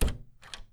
DoorOpen.wav